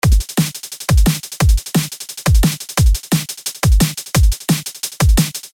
For this example, I emphasized the first, third, and fourth beats.
I’ll add a kick and snare for context and play the before and after of our hat adjustments in the audio file. The first two bars are with a flat hat velocity, and the second two bars are the pattern we made in the tutorial.